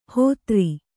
♪ hōtri